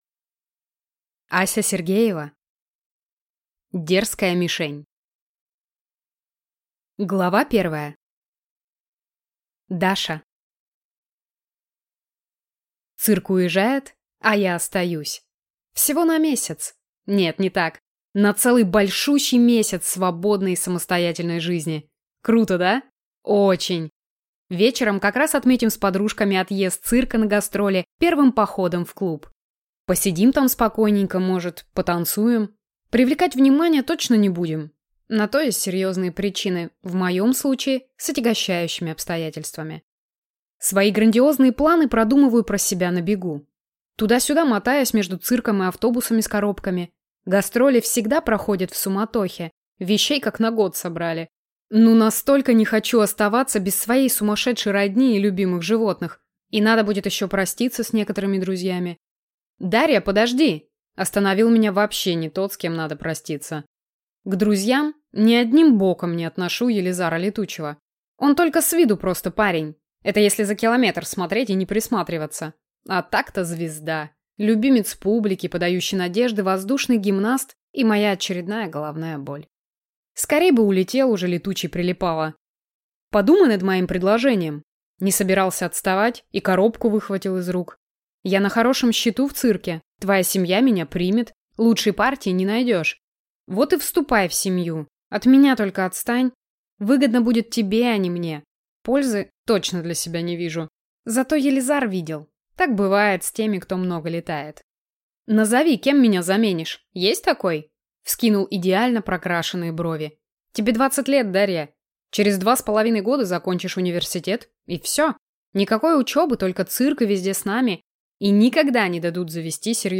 Аудиокнига Дерзкая мишень | Библиотека аудиокниг
Прослушать и бесплатно скачать фрагмент аудиокниги